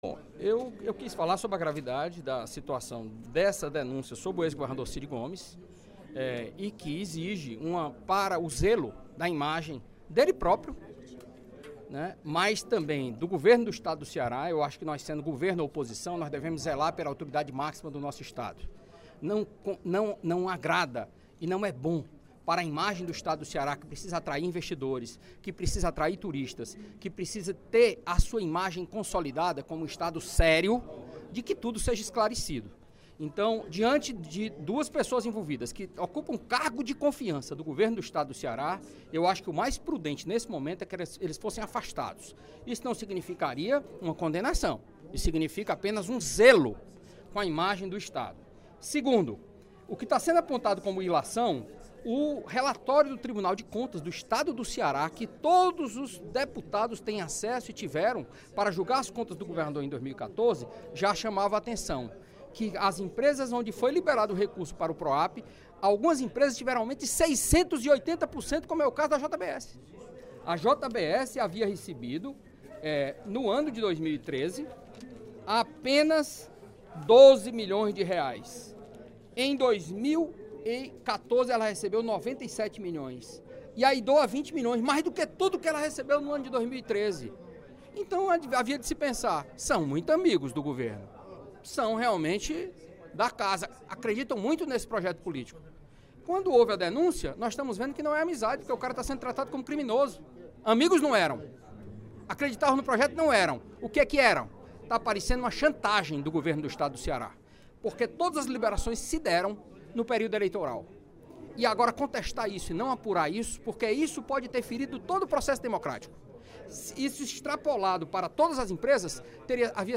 O deputado Carlos Matos (PSDB) defendeu a investigação, durante o primeiro expediente da sessão plenária desta quinta-feira (25/05), da denúncia feita pelos donos da JBS contra o ex-governador Cid Gomes.
Em aparte, o deputado Sérgio Aguiar (PDT) afirmou que Carlos Matos fez “ilações” e avaliou que a conduta do parlamentar, ao trazer o tema para a tribuna, “não foi justa”.